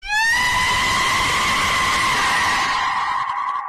eyyyaaaahhhh Meme Sound Effect
eyyyaaaahhhh.mp3